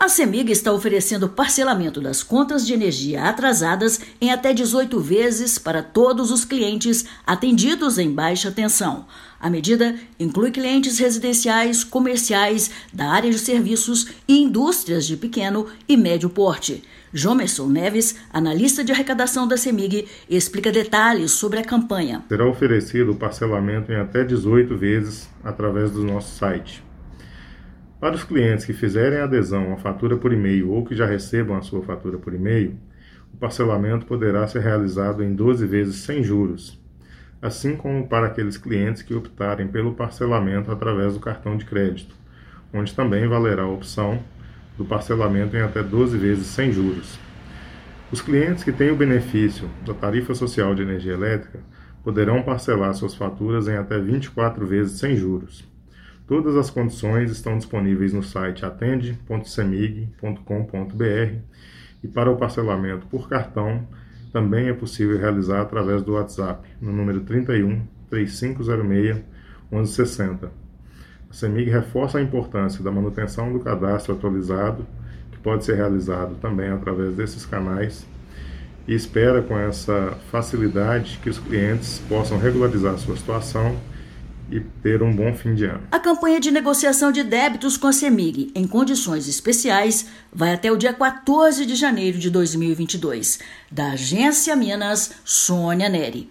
Web-rádio: Cemig inicia campanha de regularização de débitos com parcelamento